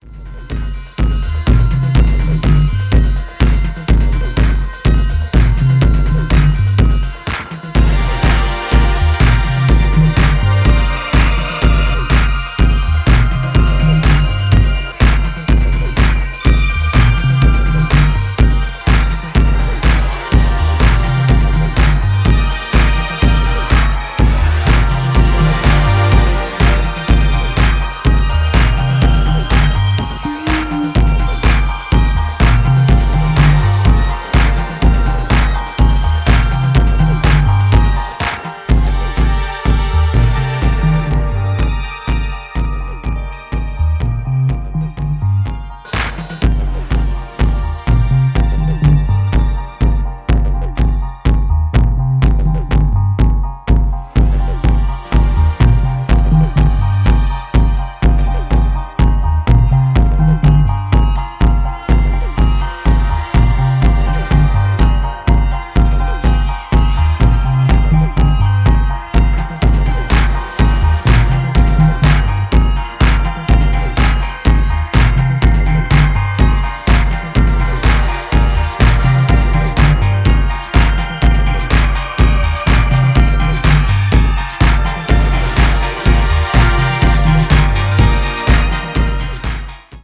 Hybrid house and techno